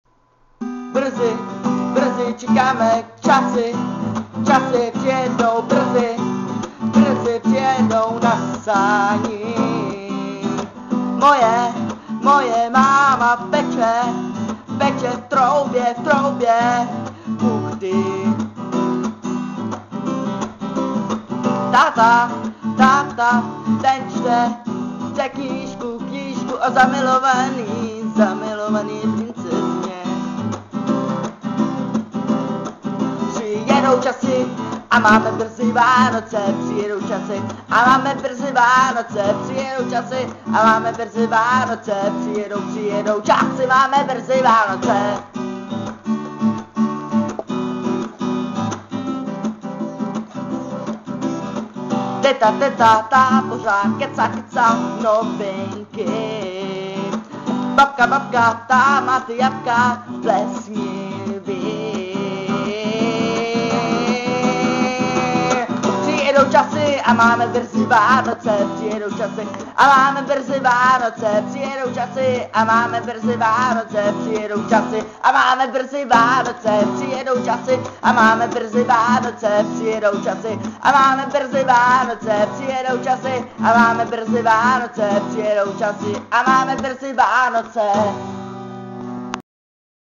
Žánr: World music/Ethno/Folk
Folkové hudební album